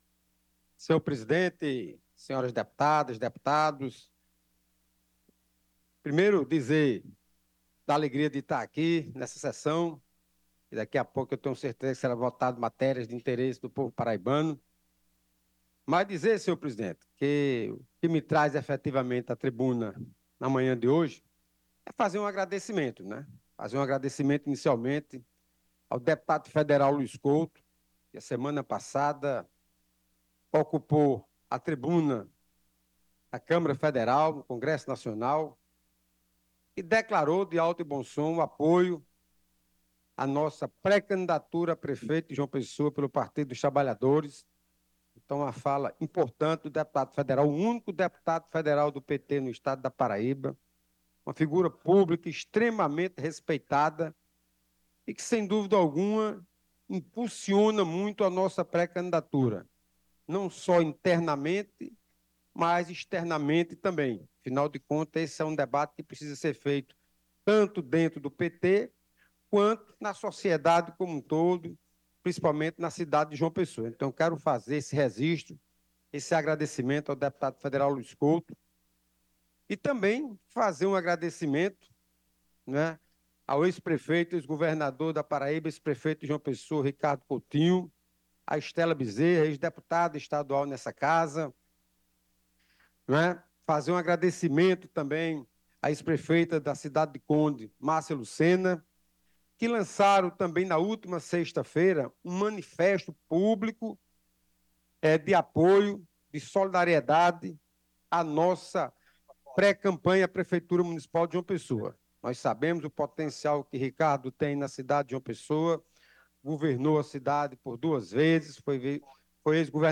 O deputado estadual Luciano Cartaxo (PT), pré-candidato à Prefeitura de João Pessoa, durante uma sessão na Assembleia Legislativa da Paraíba, agradeceu ao apoio recebido de figuras de peso do partido e destacou a sua confiança na Executiva Nacional para ratificar sua pré-candidatura.